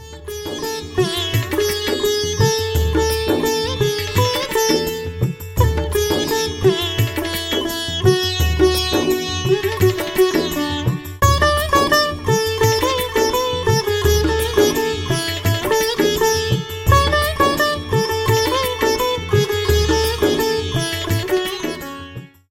Featured in Tabla Ringtones